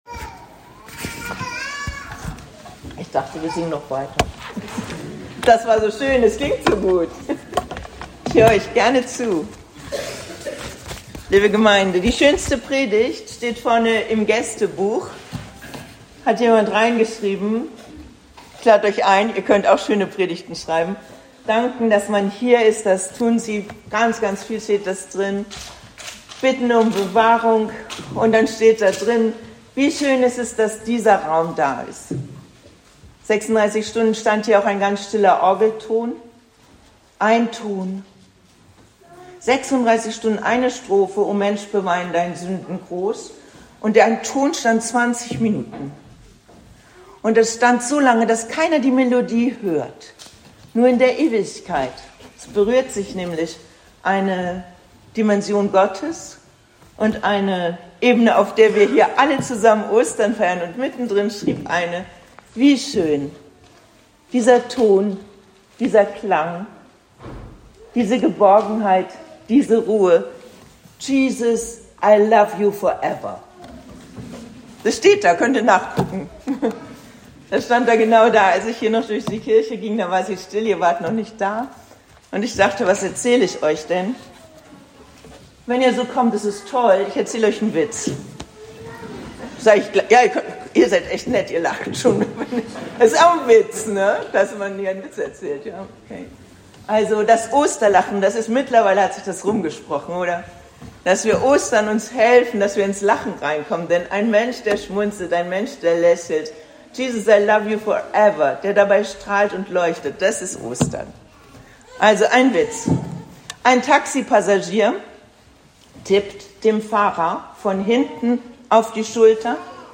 Predigt an Ostern 2025